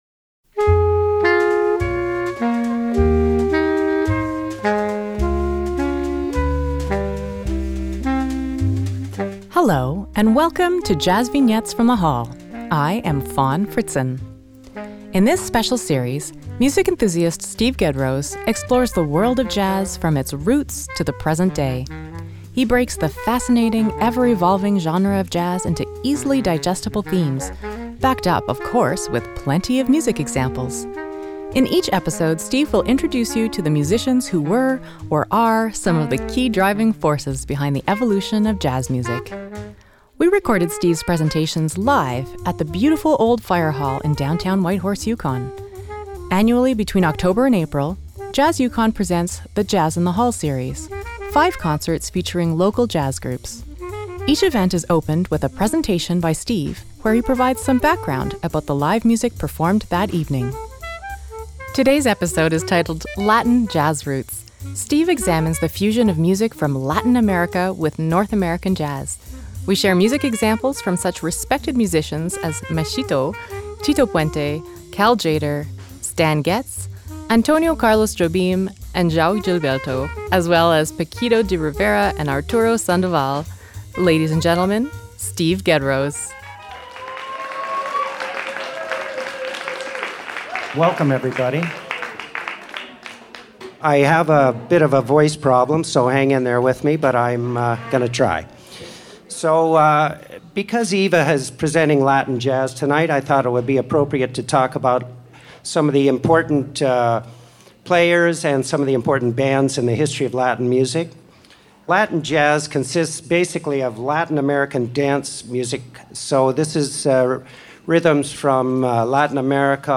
JVFTH03LatinJazzRoots_1.mp3 57,853k 256kbps Stereo Comments